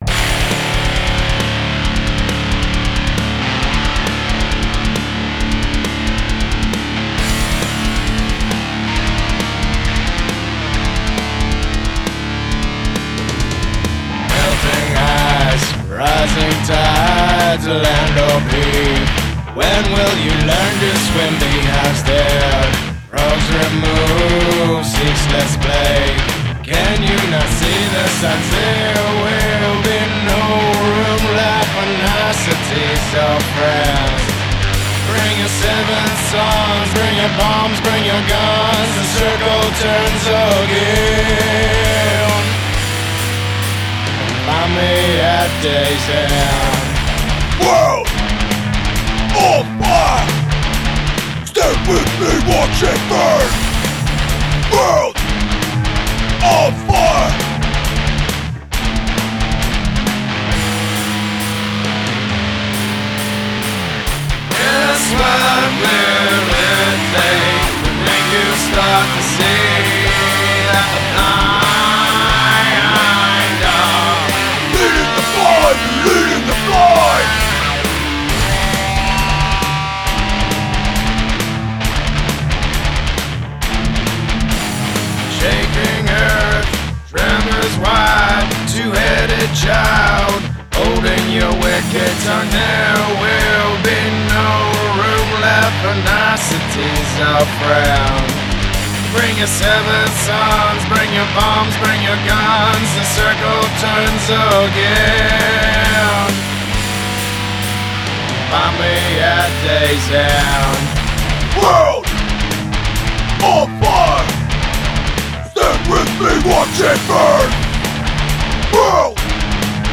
Guitars, Bass & Percussion
Vocals, Bass, Guitars & Percussion.